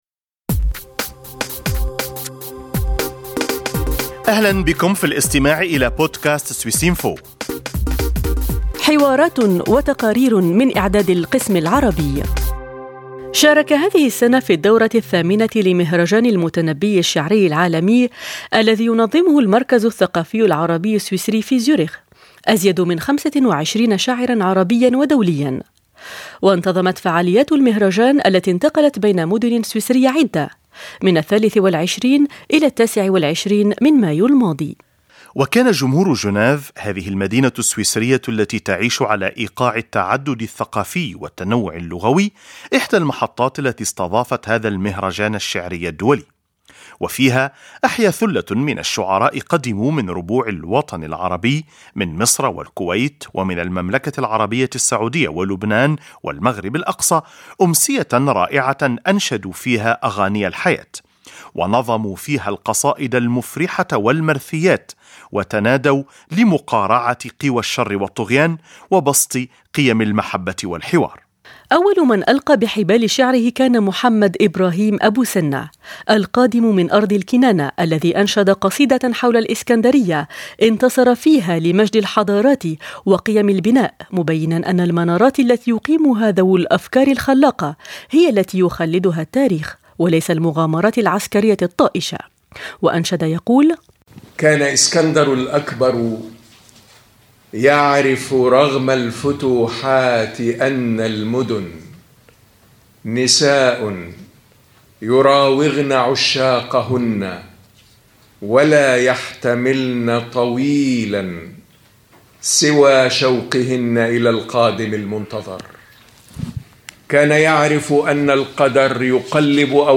في الربيع، يتجدد موعد الجمهور السويسري مع المتنبي، من خلال مهرجان الشعر العالمي الذي اختتم دورته الثامنة يوم الخميس 29 مايو 2008، وشهد مشاركة أزيد من 25 شاعرا عربيا ودوليا ألقوا المتنبي أشعارا عربية جميلة ومؤثرة في محطة جنيف بحضور جمهور سويسري وعربي.